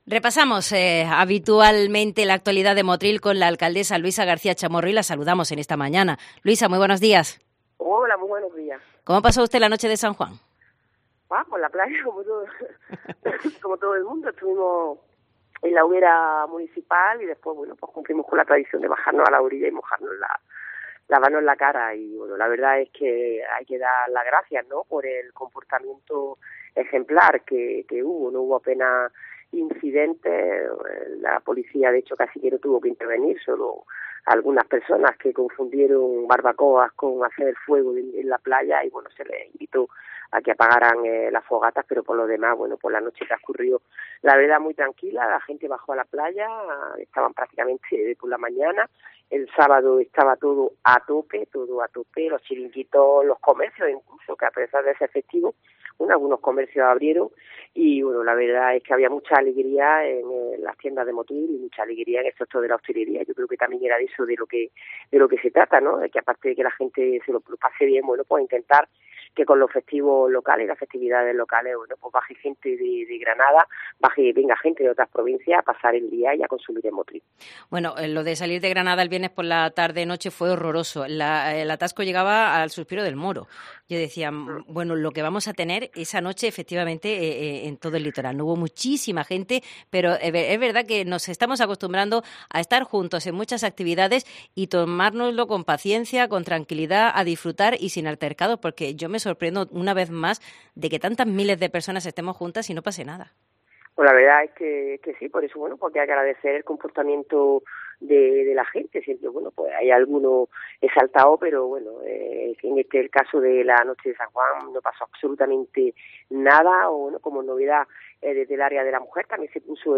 La Alcaldesa de Motril nos explica que este año los baños asistidos en la playa se prestarán a diario